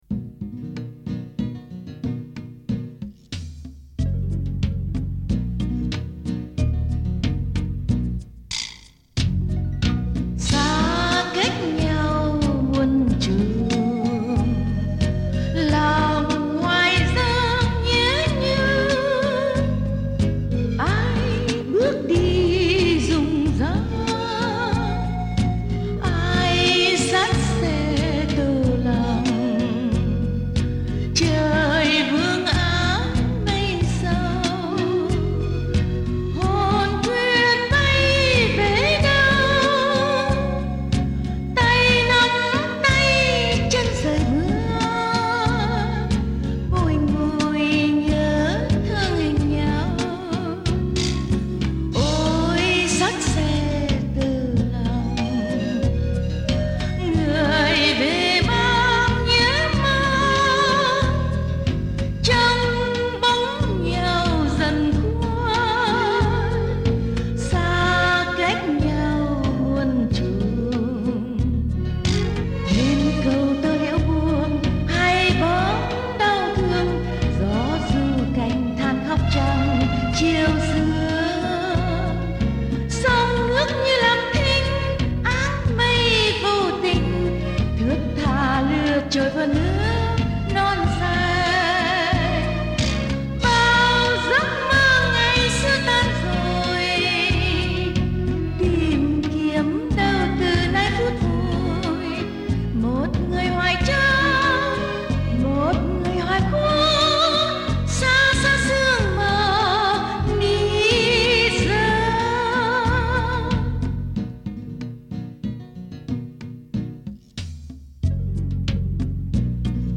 Tuyển Tập Nhạc – Nhạc Tiền Chiến – Xa cách muôn trùng – Thẩm Oánh